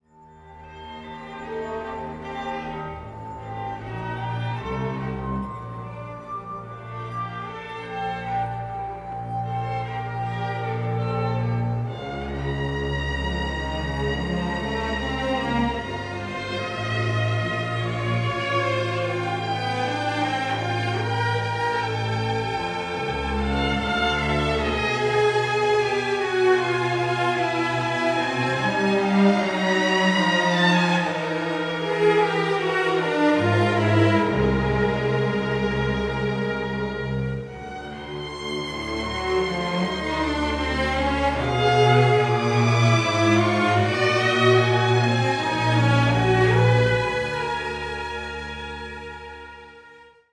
banjo
harmonica